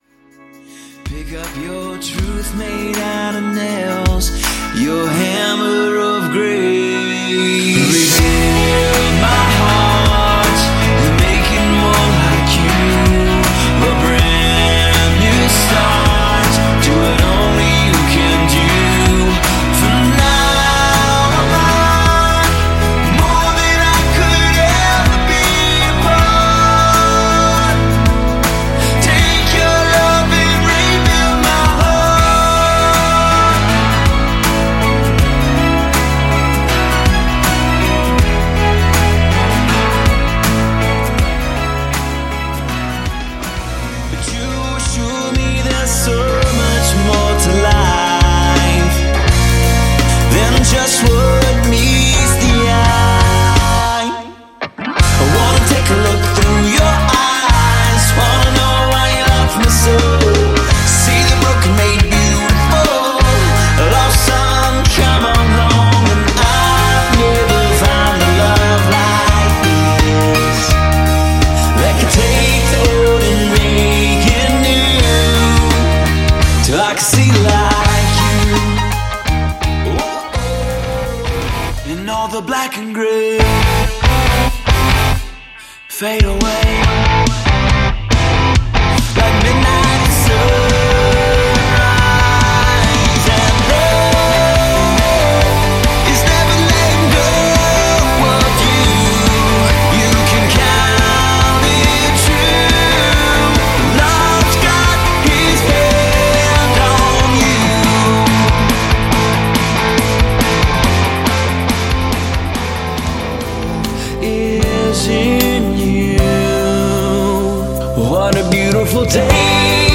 Genre: Christian Rock.